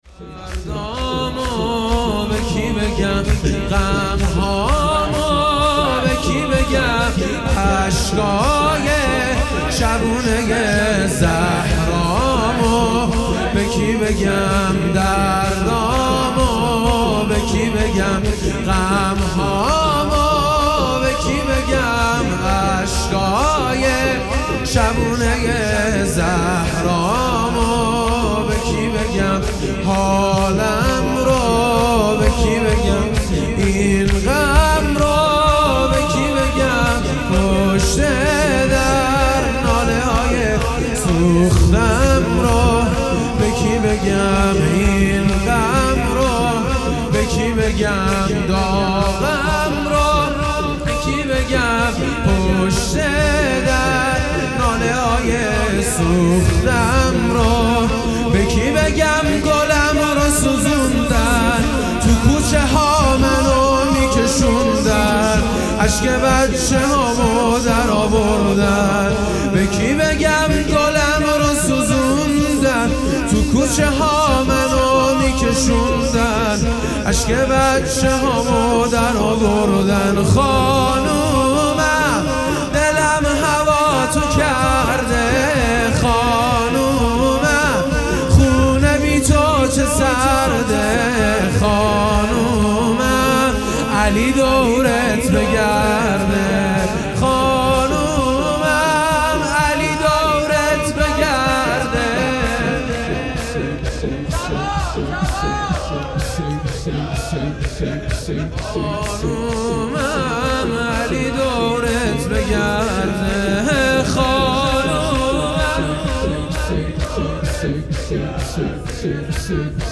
شهادت حضرت زهرا(ُس) - سه شنبه17بهمن1396